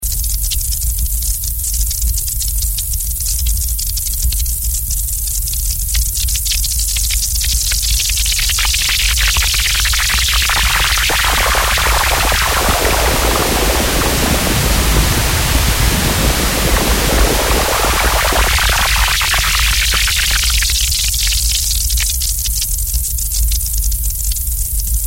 Mystery 1 - This one is actually IIRC just the CS15 doing filter FM stuff.